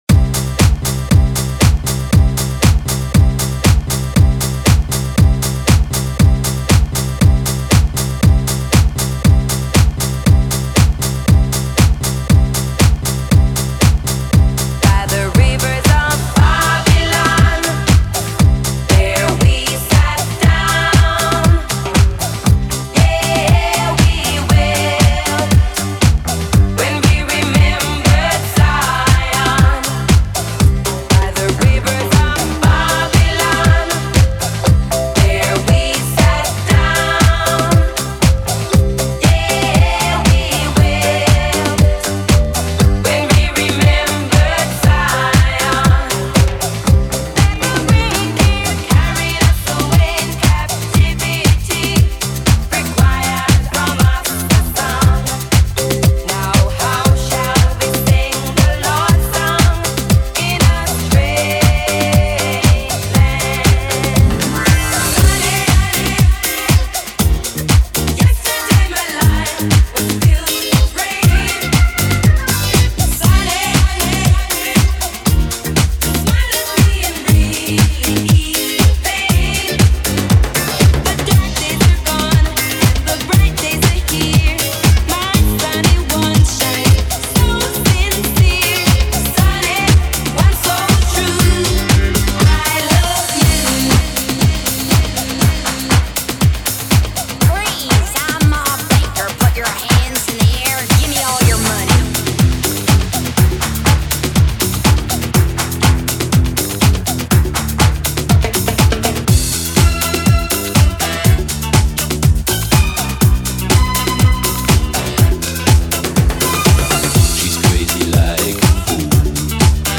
Relive the Disco Fever with Miami Vibes
an electrifying remix by Miami’s DJ & VJ